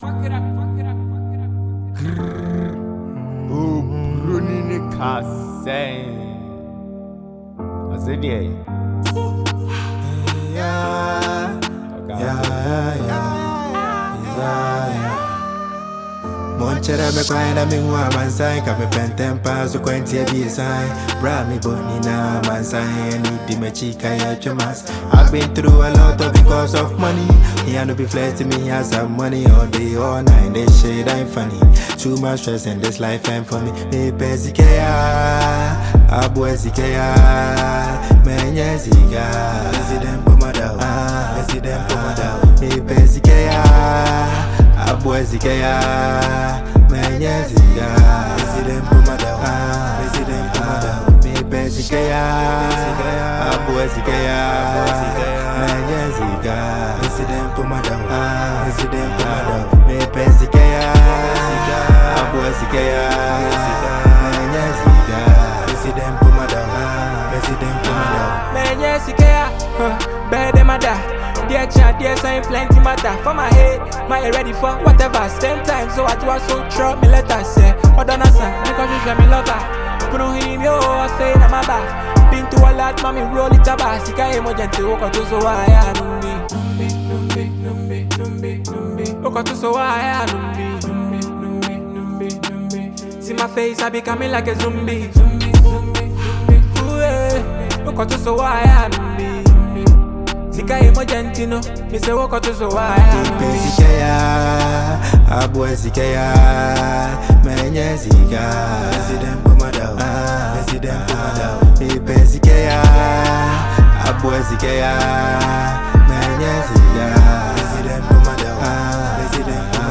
a very well-known Ghanaian trapper